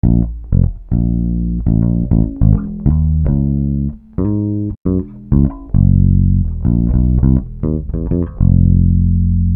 Bass 07.wav